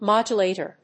音節mód・u・là・tor 発音記号・読み方
/‐ṭɚ(米国英語), ‐tə(英国英語)/